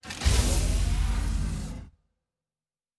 Sci-Fi Sounds / Doors and Portals / Door 5 Open.wav
Door 5 Open.wav